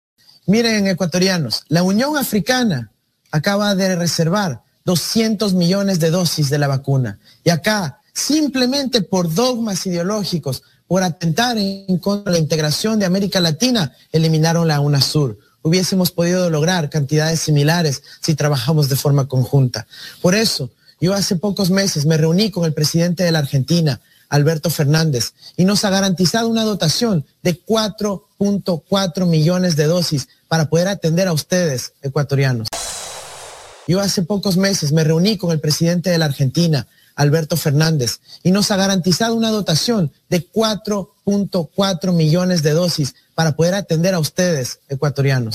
Andrés Arauz, apoyado por el ex presidente Rafael Correa, reveló durante un debate que se reunió con el presidente argentino para conversar sobre la pandemia
Los dichos, expresados en el debate presidencial que tuvo lugar el pasado 17 de enero, tuvieron repercusión en las últimas horas.